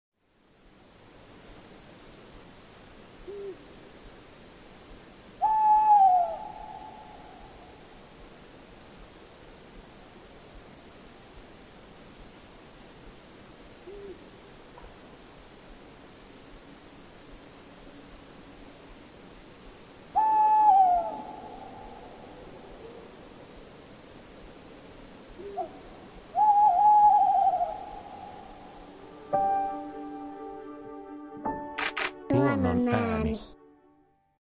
Kuulas, korkea huuto leikkaa ilman surumielisenä tai kohtalokkaana, kuulijasta riippuen.
Lehtopöllön soidinääni on sointuva, väräjävä ulina ”huuuuuo, huh u-u-u-u-u-u-uuuuuo” (3–4 s tauko välissä). Naaraan huuto on lyhyempi. Kutsuääni on kimakka ”ke-vit”, jolla naaras usein vastailee koiraan huhuiluun.